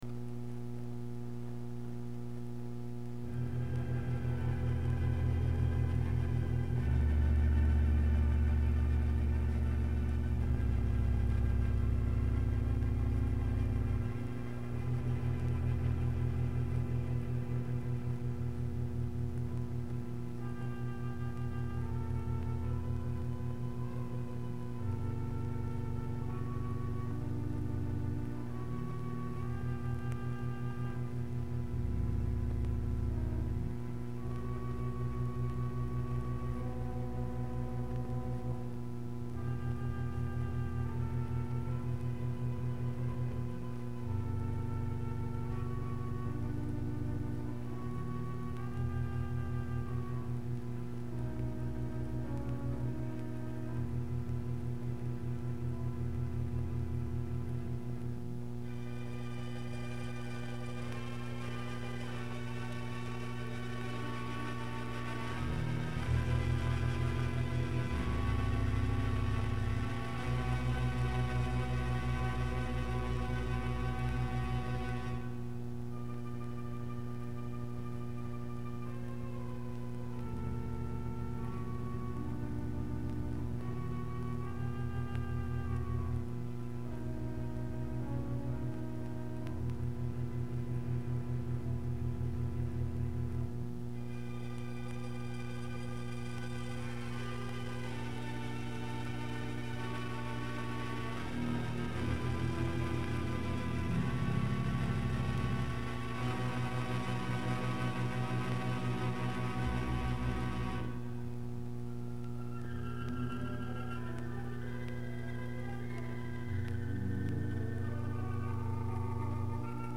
Pipe Organ Music